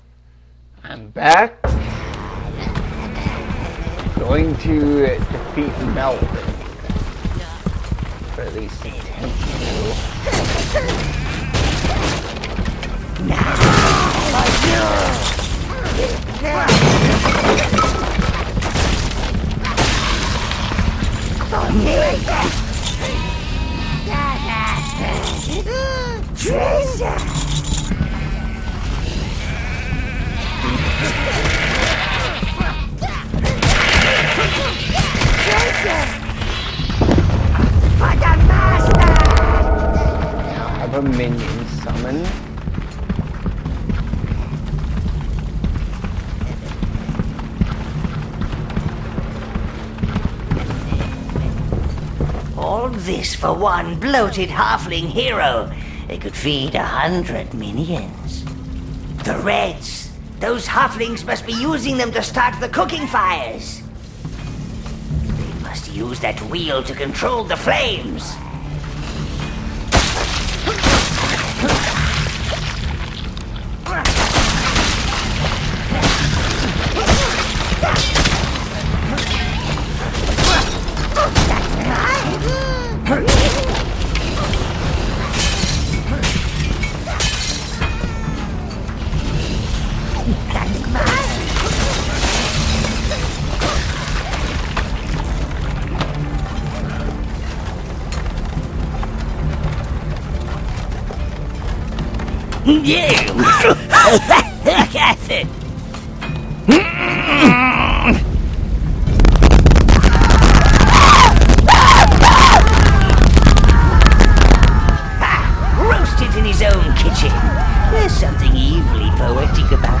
I play Overlord with commentary